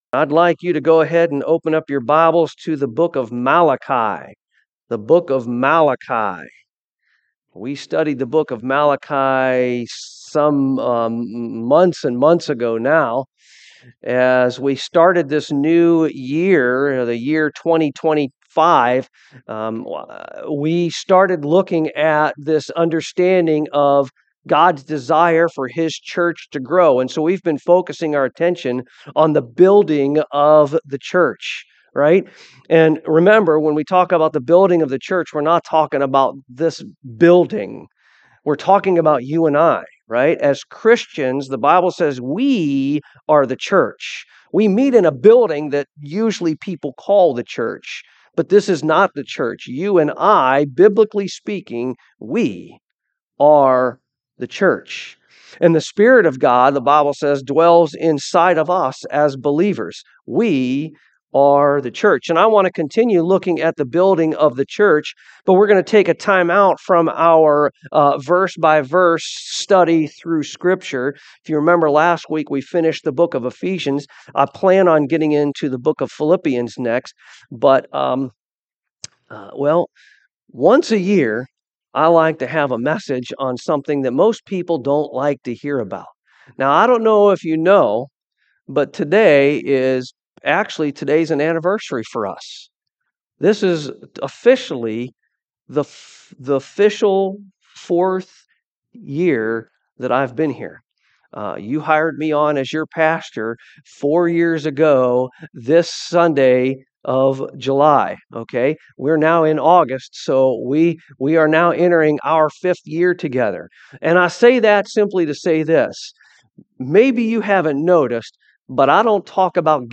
Malachi 3:8-10 Service Type: AM Will a man rob God?